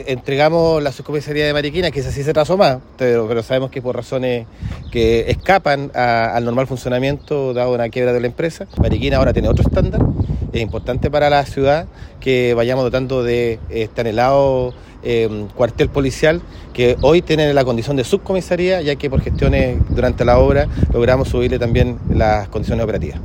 Por su parte, el delegado Presidencial, Jorge Alvial, valoró el cambio de condición de operatividad del recinto policial, que pasó de cuartel a subcomisaría, lo que se concretó durante el desarrollo del proyecto.